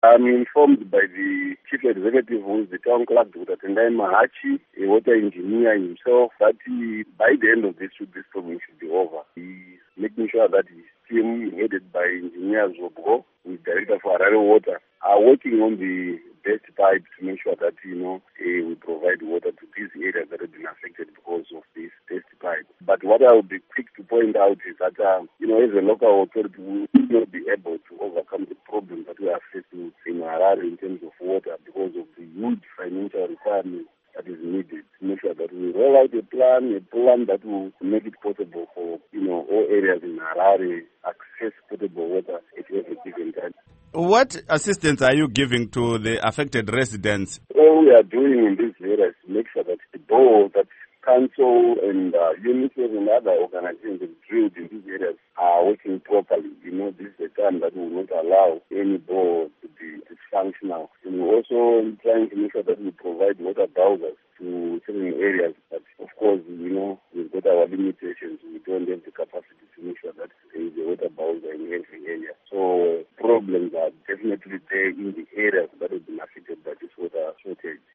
Interview With Emmanuel Chiroto